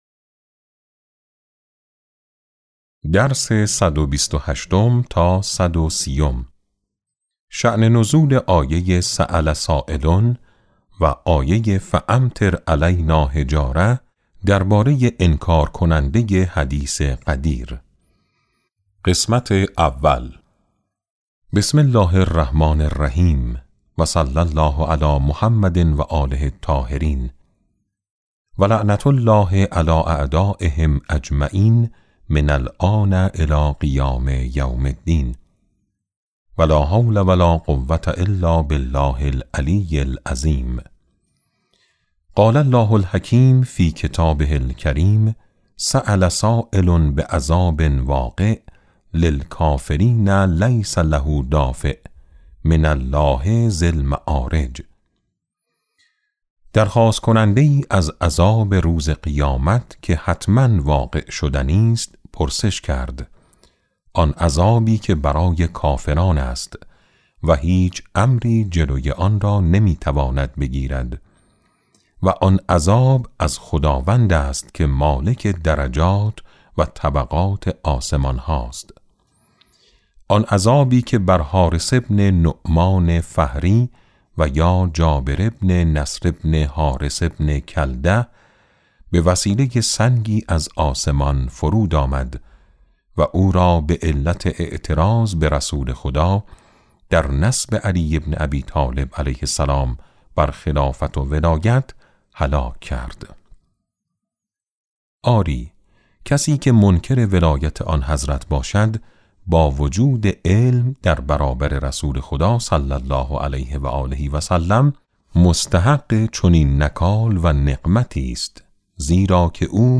کتاب صوتی امام شناسی ج9 - جلسه6